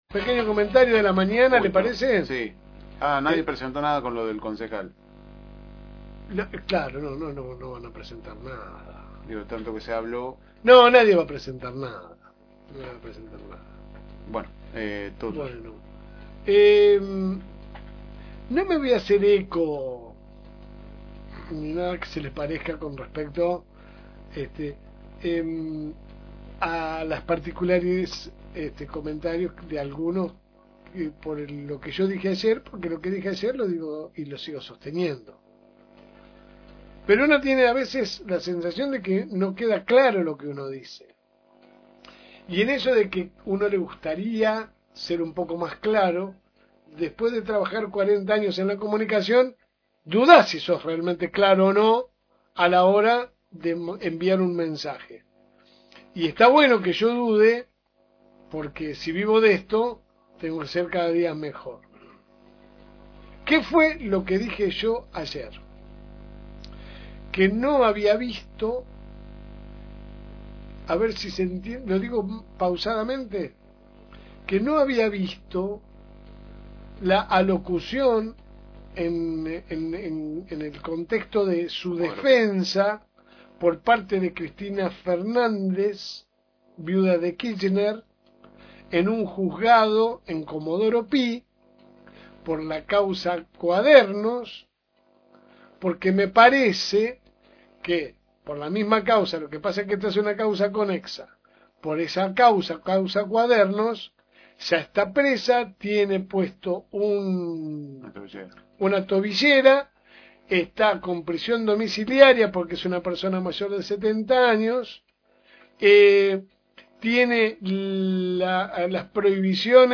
Editorial LSM